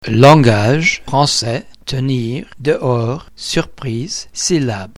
The Normal Stress Point (Independent of the speaker's psychological state)
In principle, the French so called primary "accent" or stress falls on the last syllable.